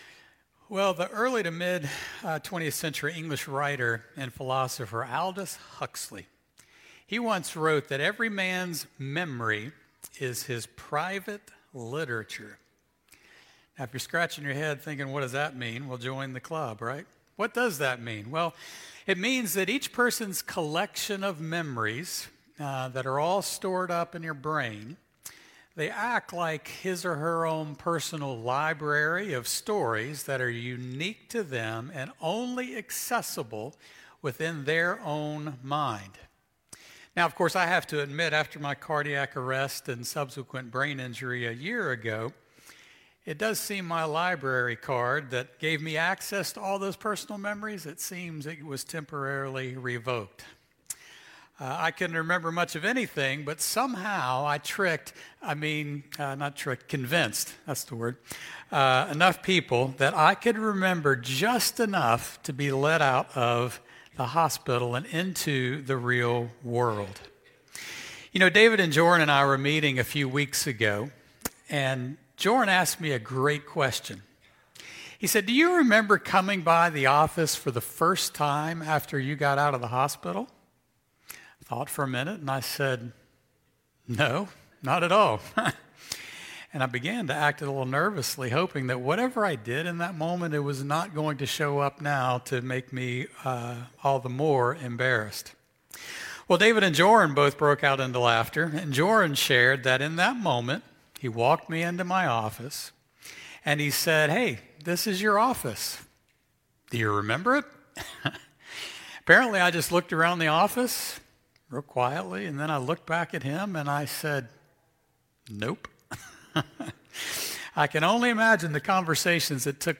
Highland Baptist Church Sermons